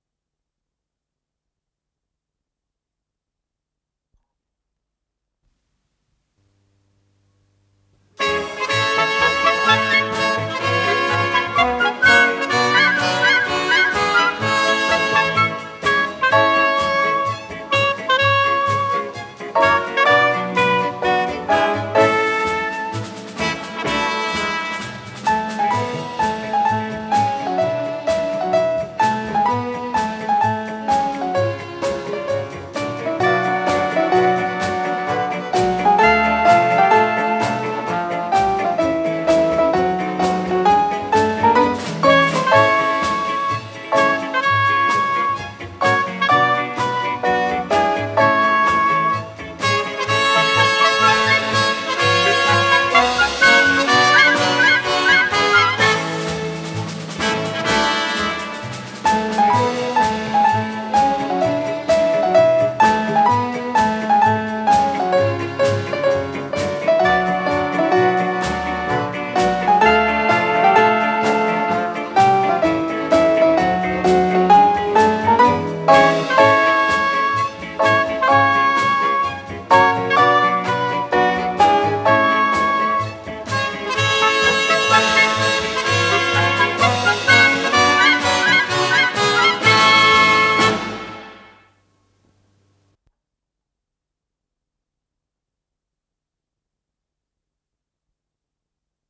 с профессиональной магнитной ленты
ПодзаголовокЗаставка, ля мажор
ВариантДубль моно